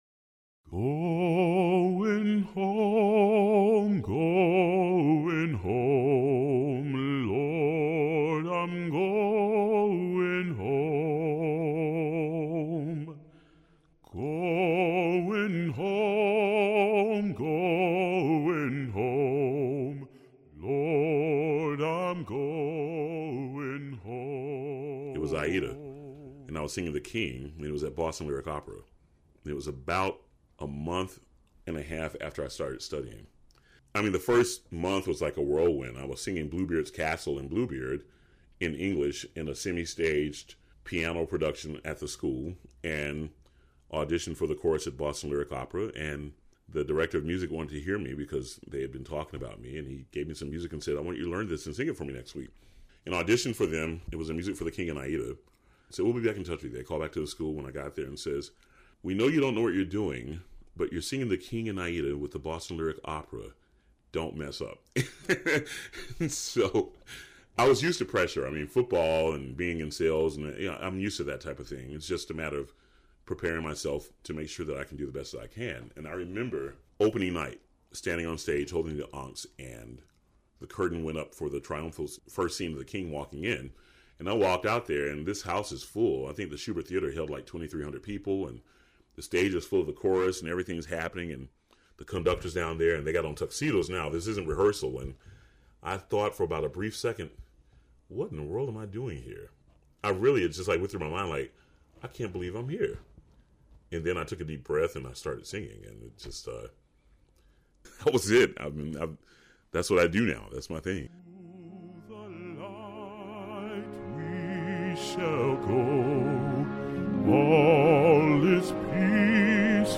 In this excerpt from the podcast, Morris Robinson remembers the experience. (And yes, that’s Morris singing. It’s from his recent CD of spirituals, Going Home.)